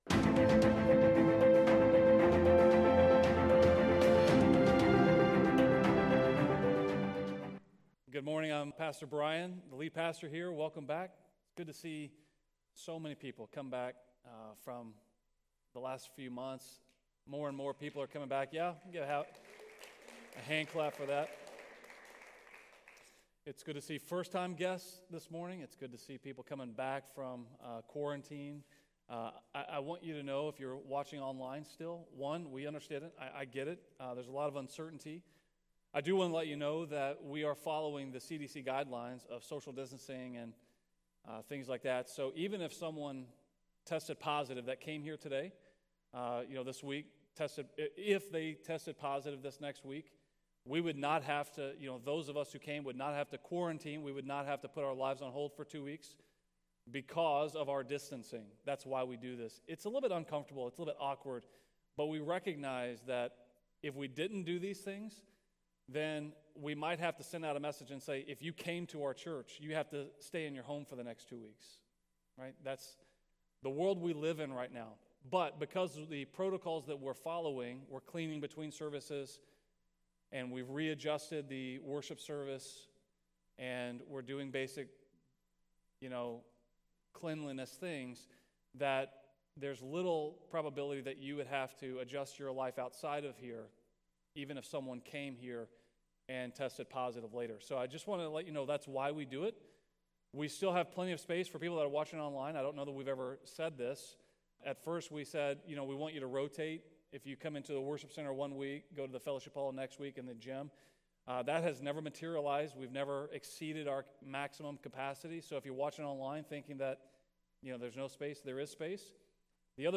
A message from the series "To Seek and To Save."